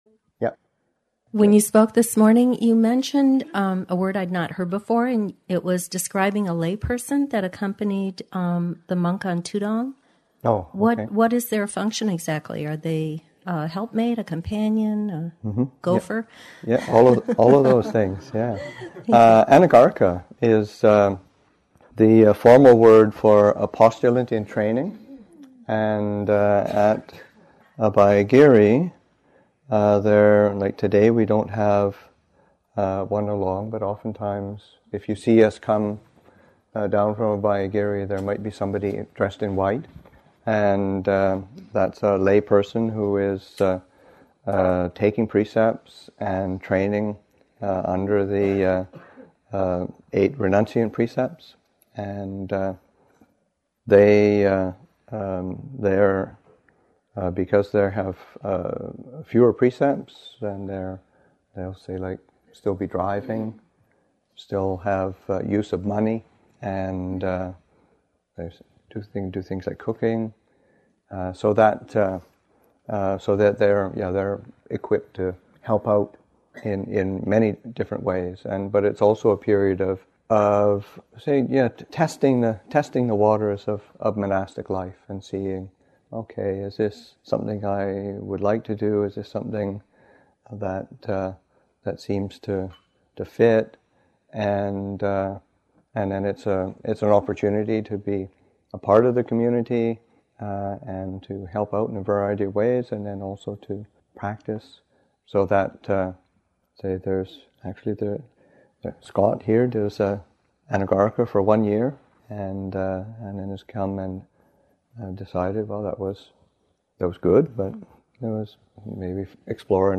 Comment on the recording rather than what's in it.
Tudong Stories at Spirit Rock, Session 2 – Jun. 2, 2011